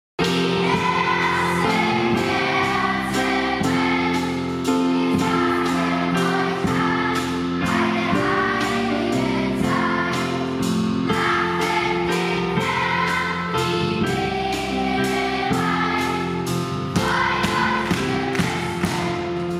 Die Schülerinnen und Schüler der Jahrgangsstufe fünf sorgen mit ihrem Gesang für ganz besondere Momente im Advent